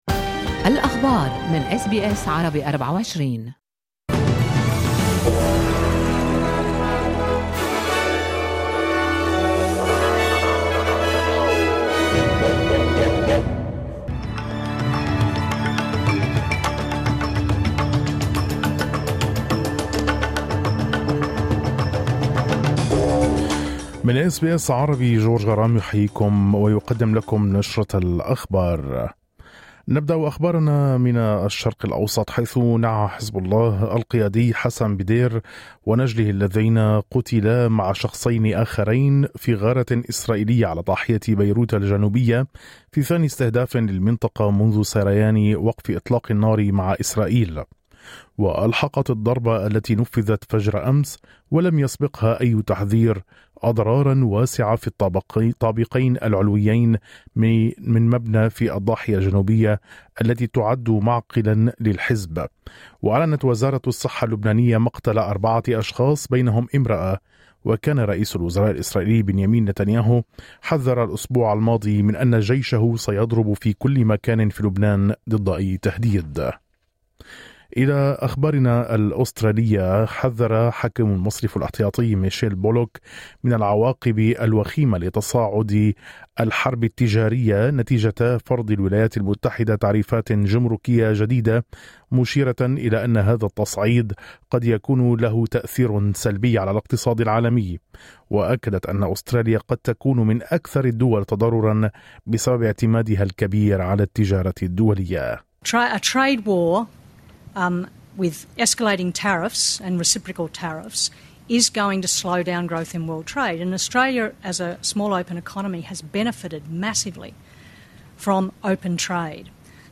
نشرة أخبار الظهيرة 02/04/2025